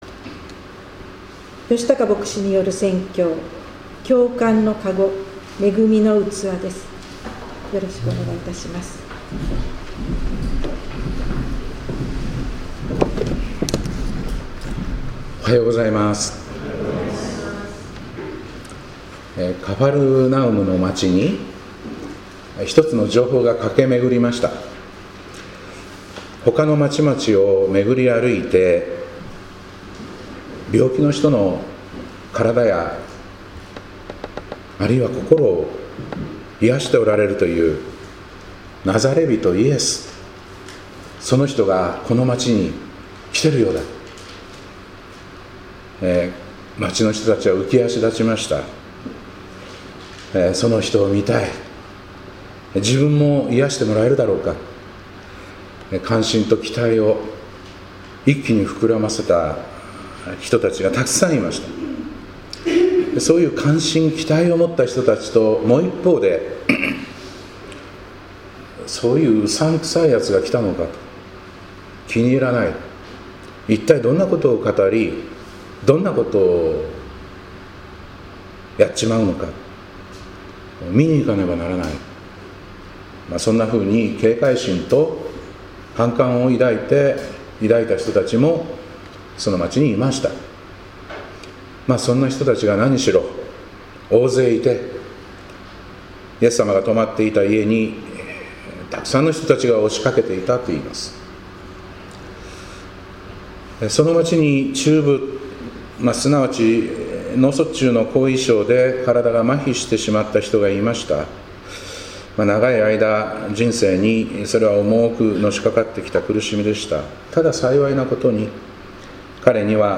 2026年1月11日礼拝「共感の籠・恵みの器」